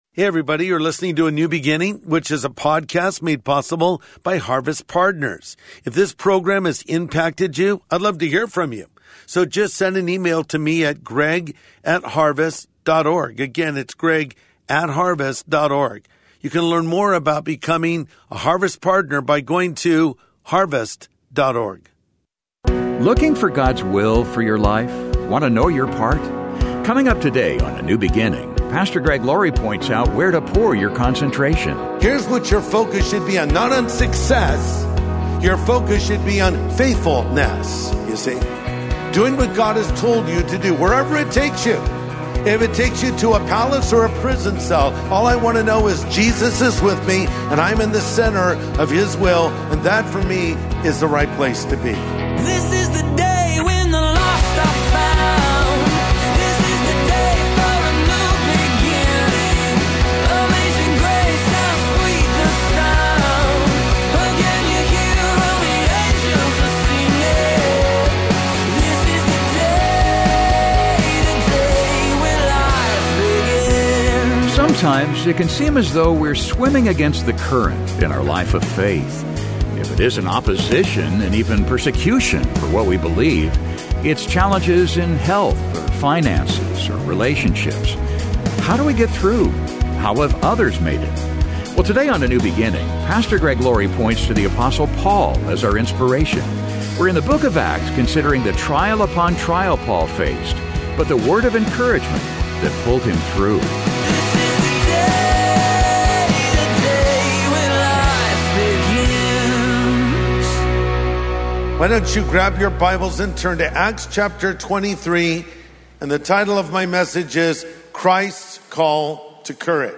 A New Beginning Â is the daily half-hour program hosted by Greg Laurie, pastor of Harvest Christian Fellowship in Southern California.